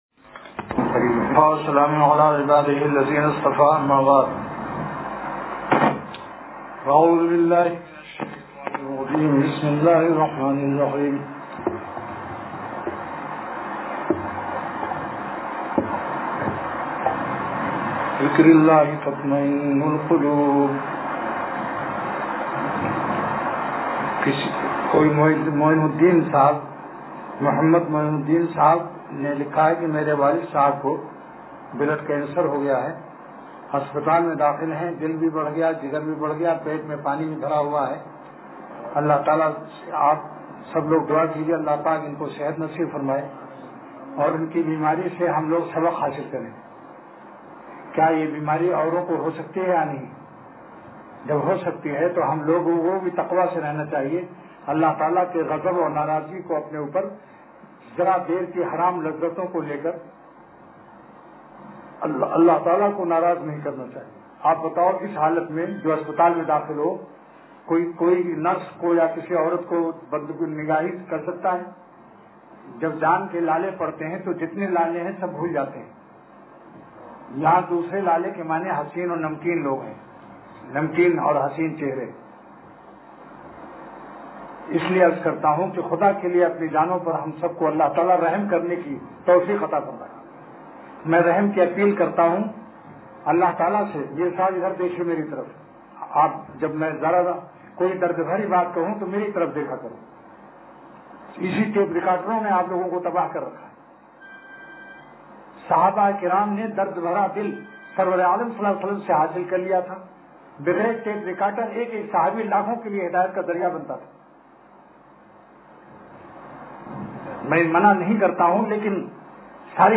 آہِ صحرا ہو مبارک ترے دیوانوں کو – اتوار مجلس